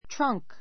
trunk trʌ́ŋk ト ラ ン ク